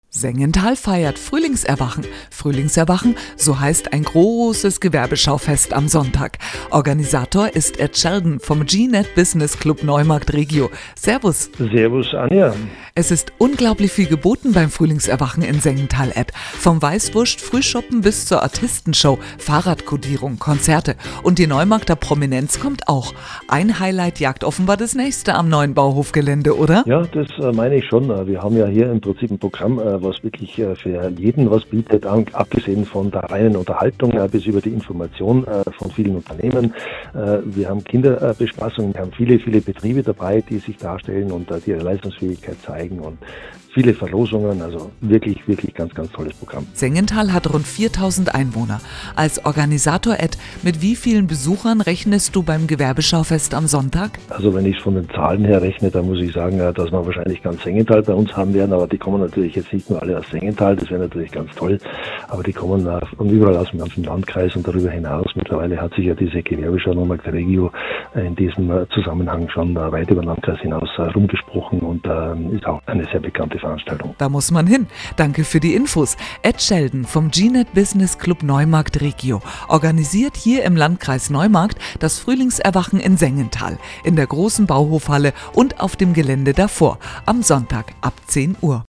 DIE EINLADUNG - EIN SPOT